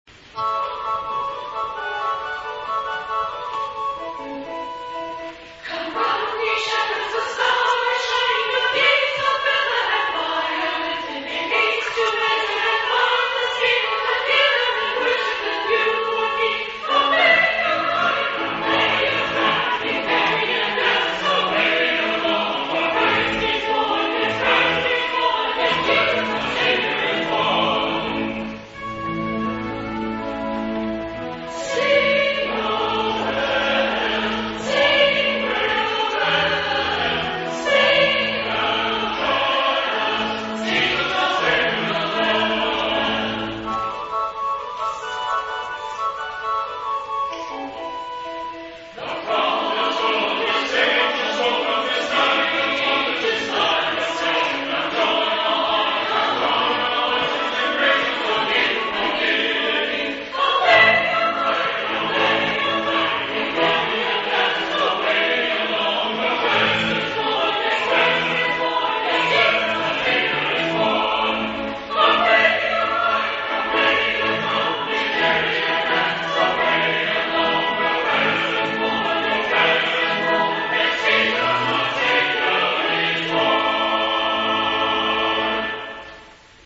"Come Run Ye Shepherds" by Hal Hopson The Second Reformed Church Christmas Concert 2009